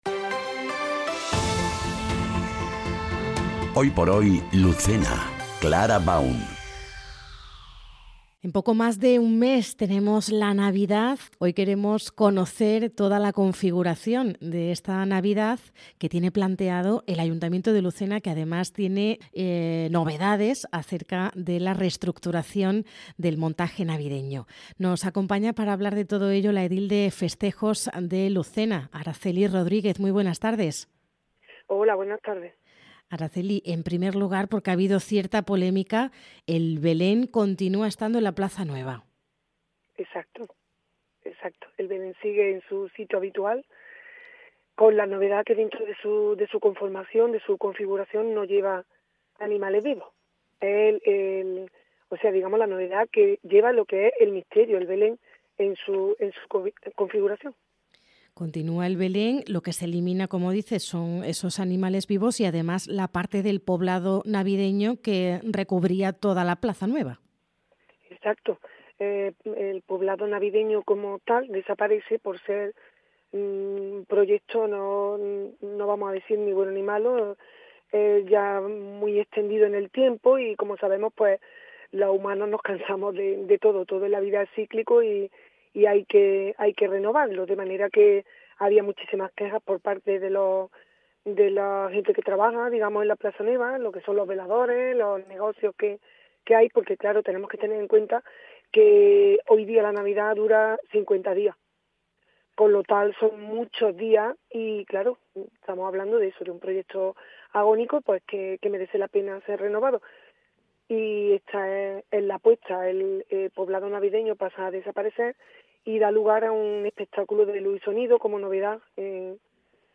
ENTREVISTA | Nuevo montaje navideño en Lucena - Andalucía Centro